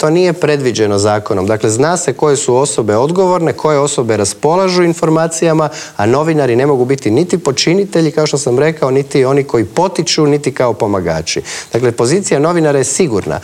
ZAGREB - Dan nakon raspuštanja 10. saziva Sabora i uoči odluke predsjednika Zorana Milanovića da će se parlamentarni izbori održati u srijedu 17. travnja, u Intervjuu tjedna Media servisa gostovao je predsjednik Sabora Gordan Jandroković.